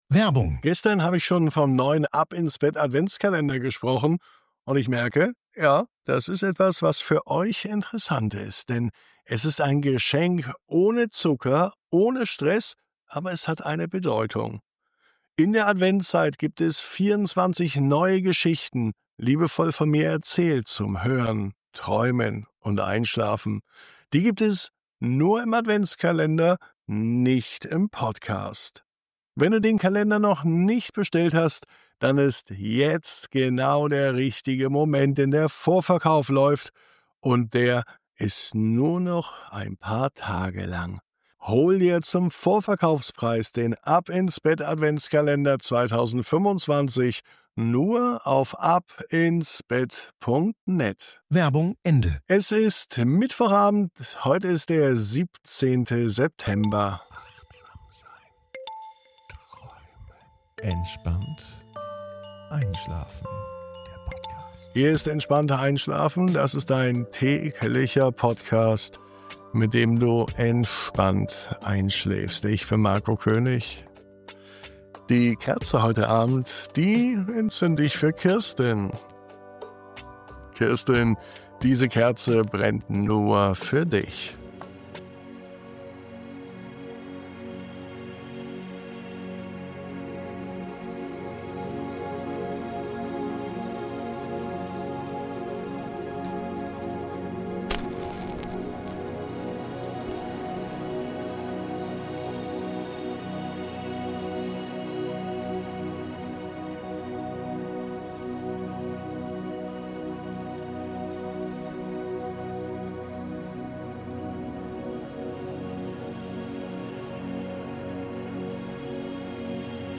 Ein knisterndes Feuer unter dem Sternenhimmel – die Flammen tanzen, die Wärme breitet sich aus, und in ihrem Licht findest du Ruhe. Diese Traumreise begleitet dich zu einem Ort der Geborgenheit, an dem Gedanken verschwinden dürfen und du einfach loslassen kannst.